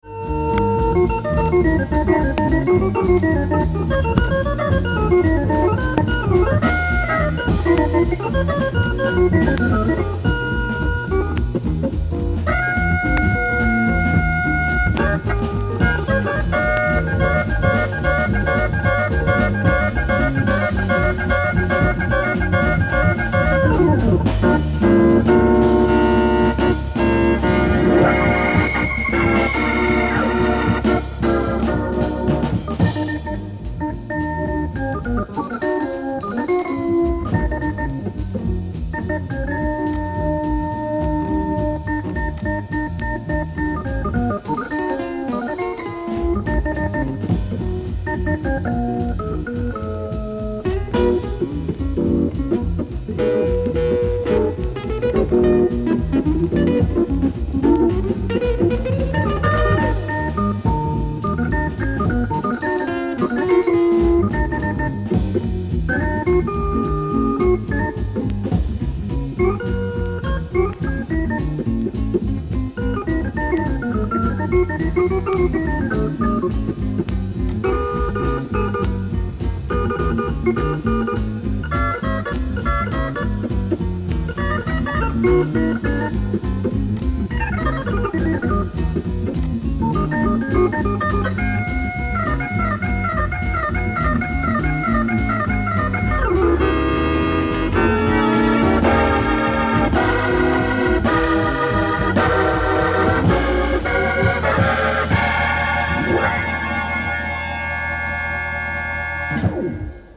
trop de Leslie pour Auger, j'en doute,mais bon, qui sait!
Le son arrache tout de même.